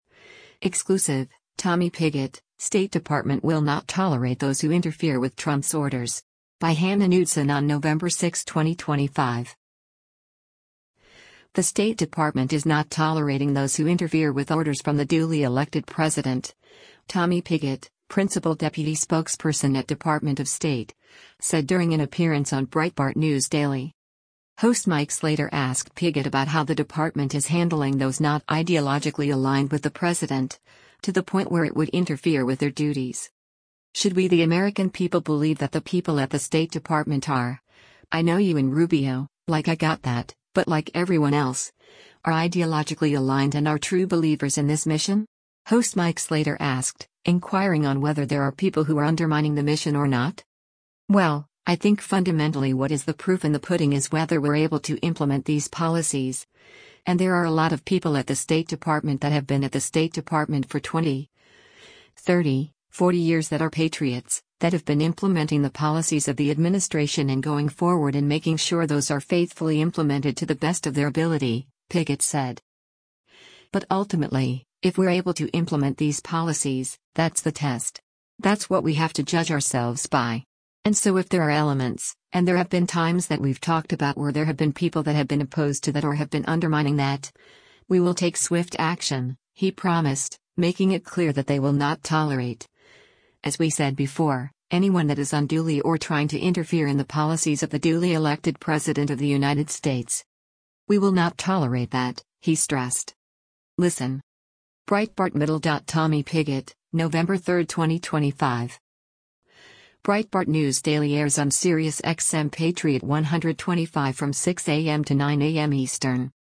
The State Department is not tolerating those who interfere with orders from the duly elected president, Tommy Pigott, Principal Deputy Spokesperson at Department of State, said during an appearance on Breitbart News Daily.
Breitbart News Daily airs on SiriusXM Patriot 125 from 6:00 a.m. to 9:00 a.m. Eastern.